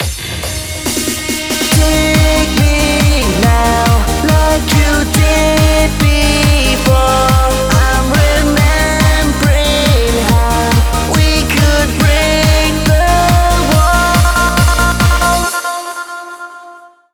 Vocal-Hook Kits